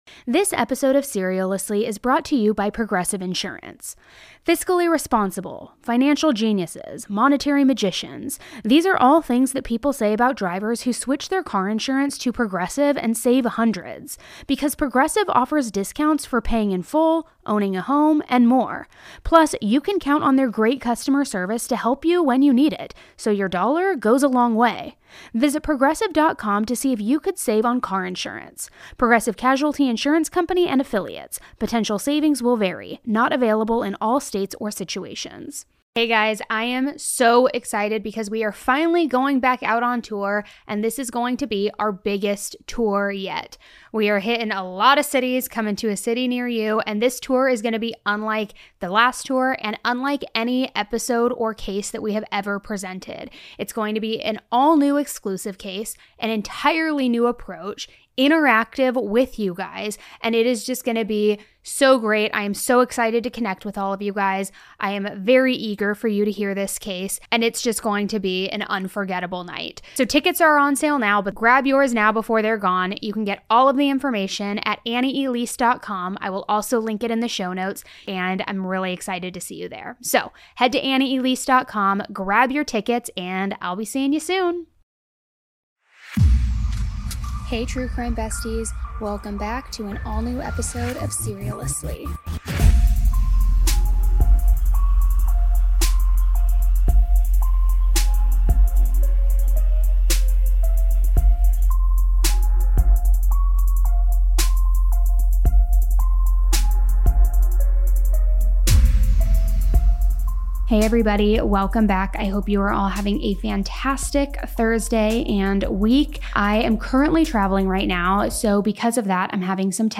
What really happened behind the headlines? In a raw new interview, Elizabeth Chambers steps into the Serialously Studio and is finally breaking her silence.